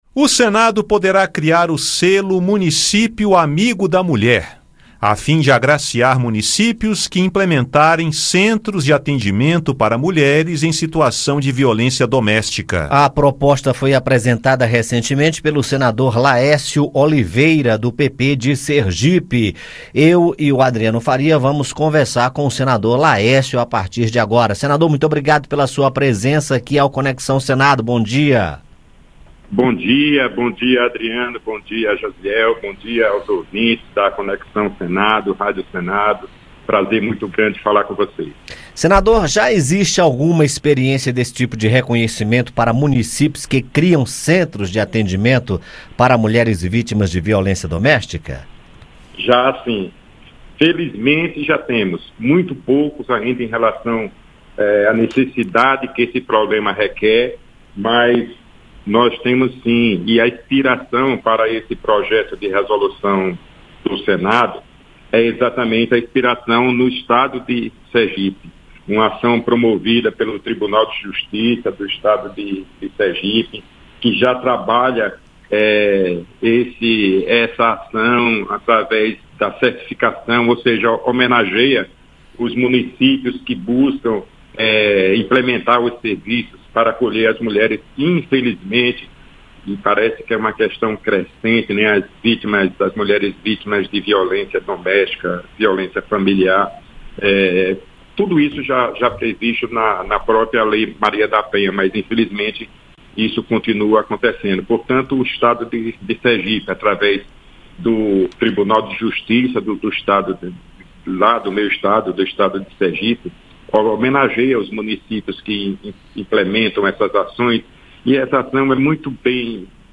Proposta apresentada recentemente pelo senador Laércio Oliveira (PP-SE) pretende criar o selo Município Amigo da Mulher (PRS 76/2023), a fim de agraciar prefeituras que implementarem centros de atendimento para mulheres em situação de violência doméstica. Em entrevista, o senador explica a proposta, a importância dos centros no combate à violência doméstica e como o selo poderá incentivar municípios a criarem esses centros ou melhorar os já existentes.